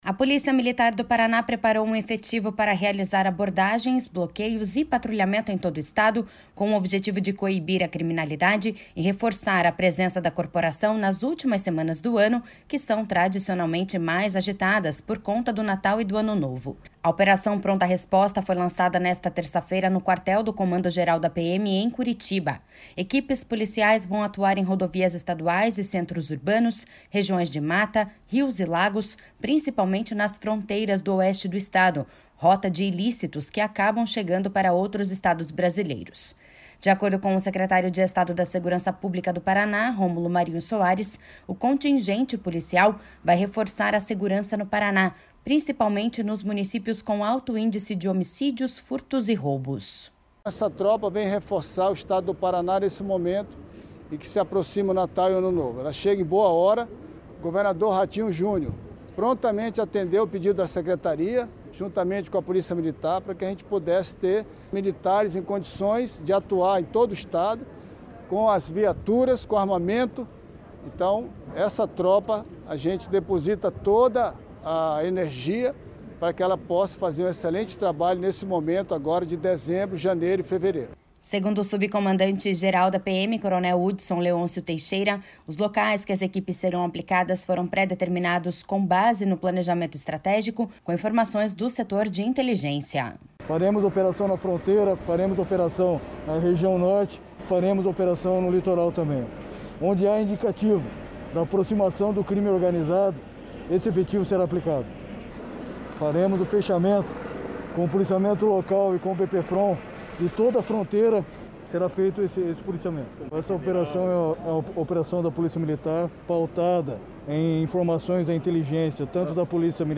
De acordo com o secretário de Estado da Segurança Pública do Paraná, Romulo Marinho Soares, o contingente policial vai reforçar a segurança no Paraná, principalmente nos municípios com alto índice de homicídios, furtos e roubos.// SONORA ROMULO MARINHO SOARES.//
Segundo o subcomandante-geral da PM, coronel Hudson Leôncio Teixeira, os locais que as equipes serão aplicadas foram pré-determinados com base no planejamento estratégico, com informações do Setor de Inteligência.// SONORA HUDSON LEÔNCIO TEIXEIRA.//